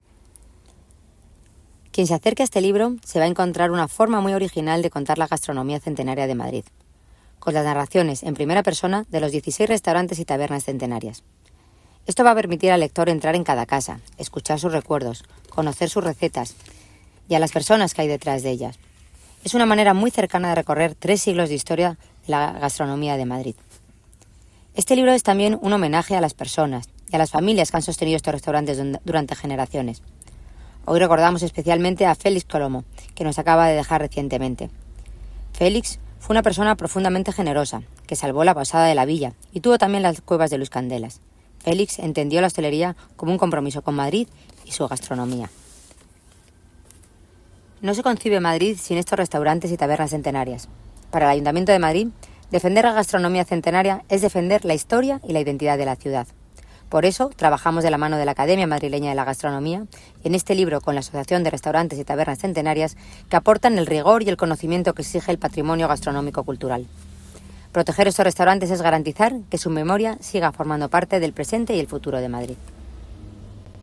La concejala delegada de Turismo, Almudena Maíllo, ha presentado hoy en Casa Ciriaco el libro Gastronomía de las tabernas y restaurantes centenarios de Madrid, una publicación que recorre casi tres siglos de historia gastronómica a través de 16 establecimientos emblemáticos de la ciudad.